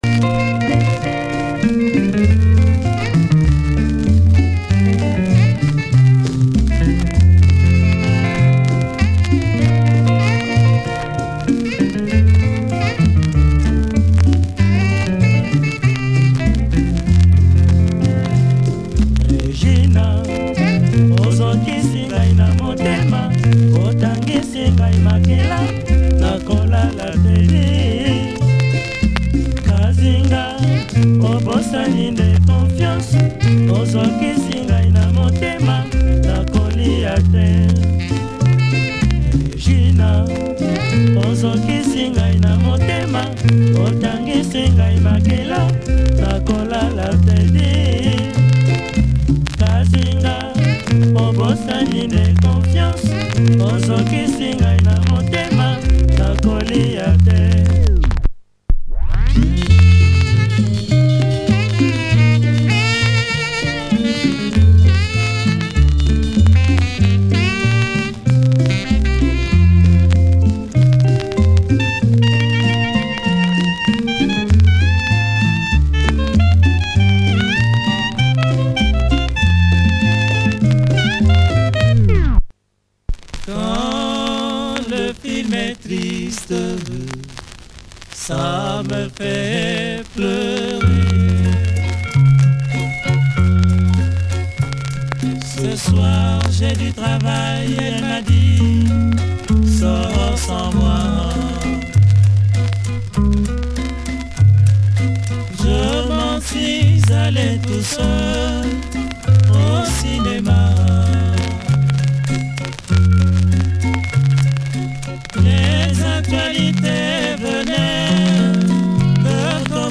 Again much dirt in the groove, needs deep clean!